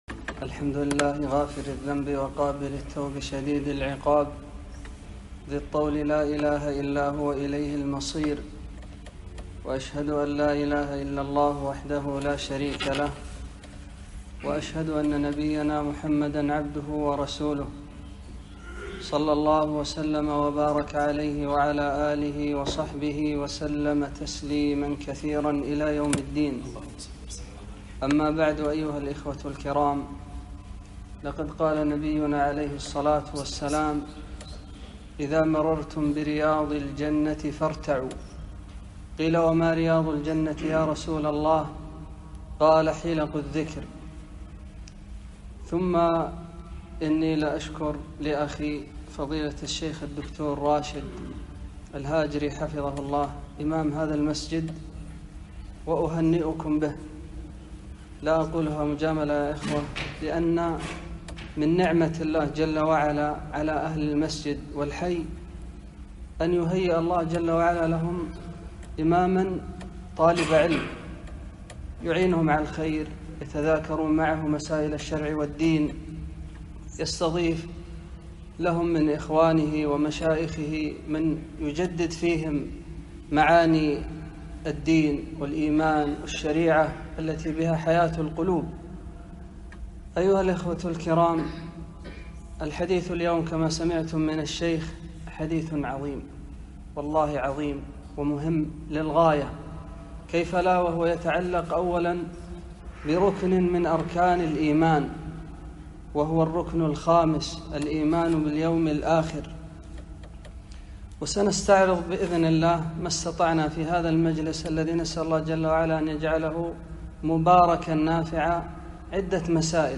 محاضرة - الإيمان باليوم الآخر